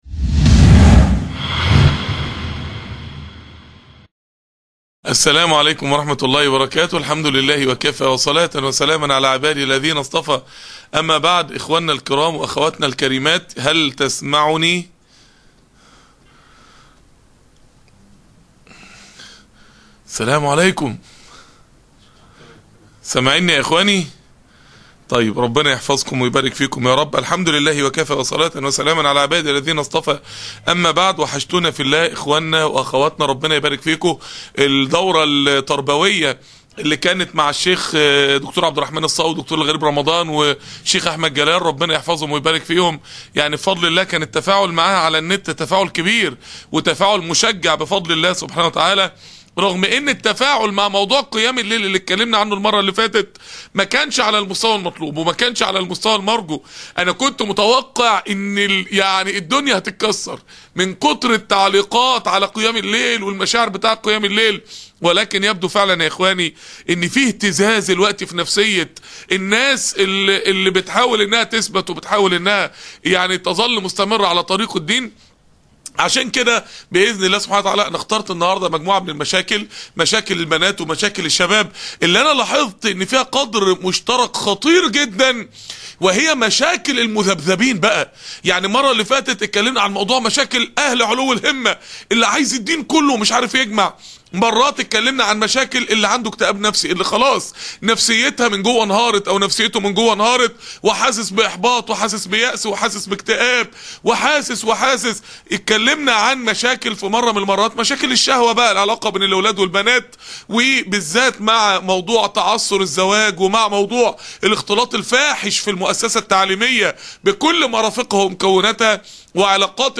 درس الغرفة الدعوية